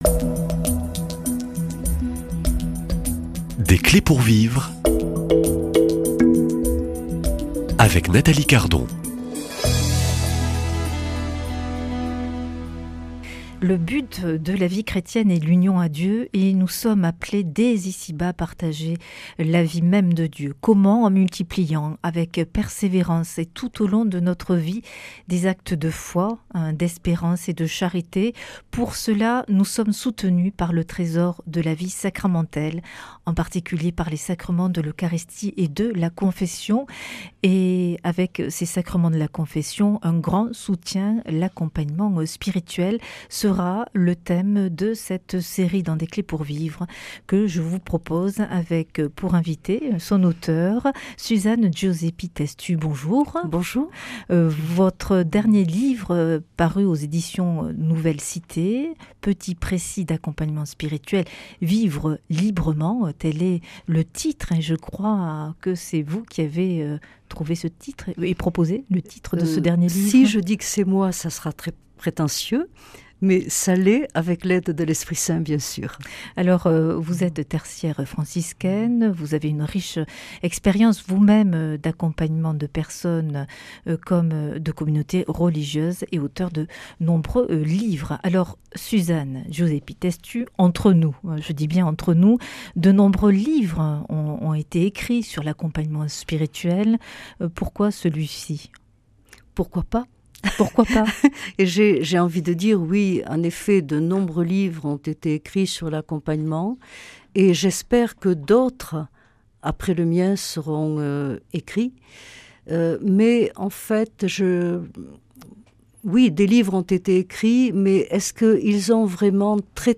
[ Rediffusion ] Pourquoi un livre sur l’accompagnement spirituel ?